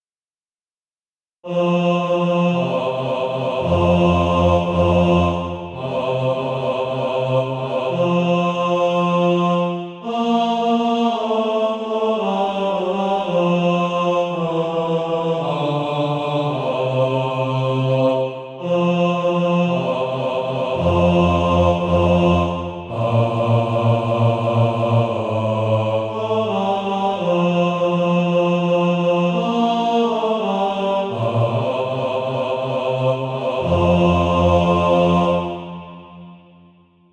(SATB) Author
Practice then with the Chord quietly in the background.